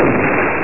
Door Close Sound Effect
Download a high-quality door close sound effect.
door-close.mp3